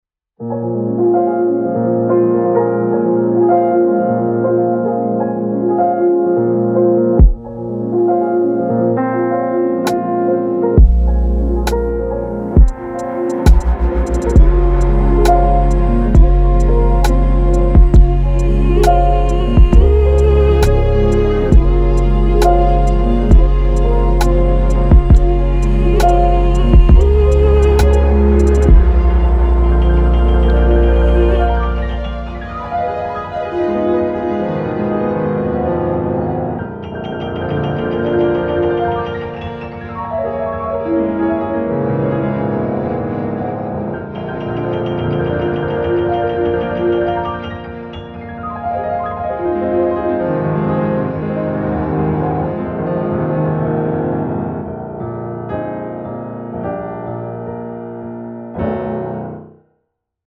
Teletone Audio Golden Age Grand是一款钢琴虚拟乐器插件，它可以让你体验到20世纪50年代和60年代钢琴独奏的黄金时代的音色。
- 它使用了一架雅马哈大钢琴的样本，这架钢琴经过了精心的老化处理，具有独特的个性和魅力。
- 它通过复古的麦克风、前置放大器和定制的复古控制台进行了录制，使你能够直接感受到黄金时代的声音。
- 磁带样本集是将样本录制到2英寸的模拟磁带上，然后将播放速度降低到27ips，使钢琴的声音更加温暖和自然。
- 数字样本集是将样本进行数字时间拉伸和降调一个小三度，使钢琴的声音更加梦幻和空灵。